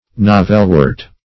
Search Result for " navelwort" : The Collaborative International Dictionary of English v.0.48: Navelwort \Na"vel*wort`\, n. (Bot.)